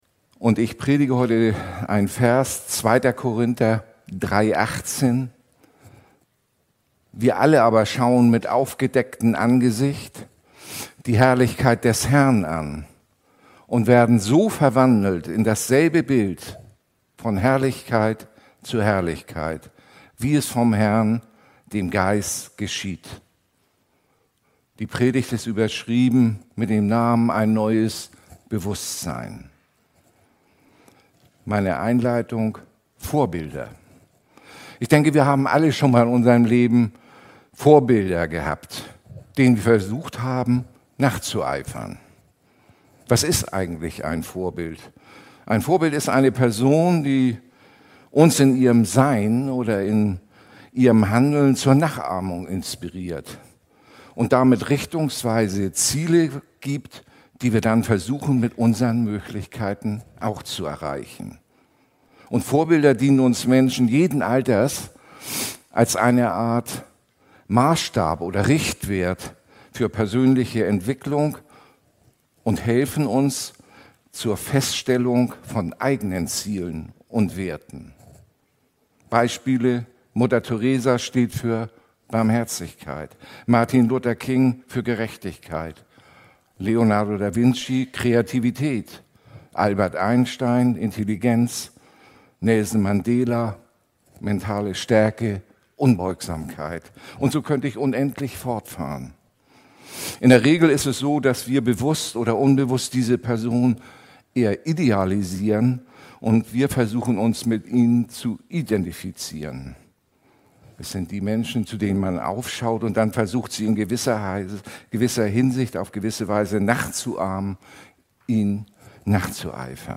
Die Predigt hat drei Punkte: 1. Jesus unser Vorbild 2. Bewustseinsveränderung 3. Das Wort und der Glaube Die Videoqualität ist eingeschränkt, da nur die Totale-Kamera eingesetzt wurde.